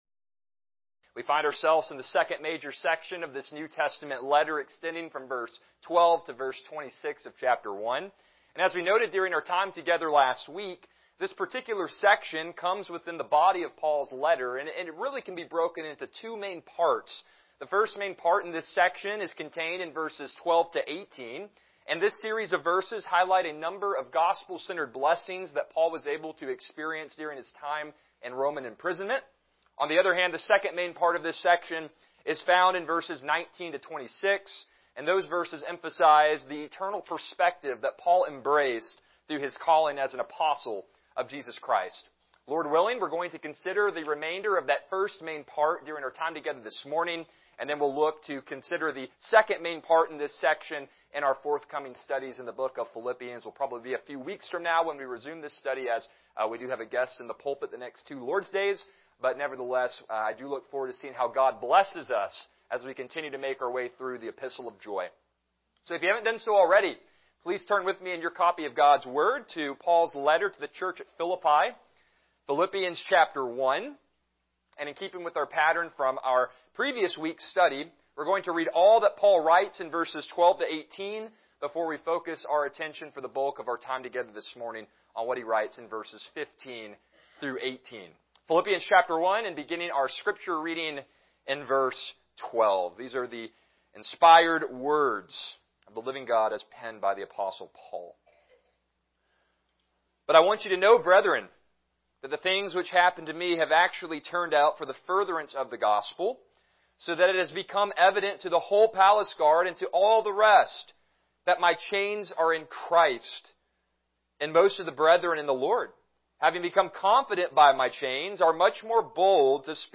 Passage: Philippians 1:15-18 Service Type: Morning Worship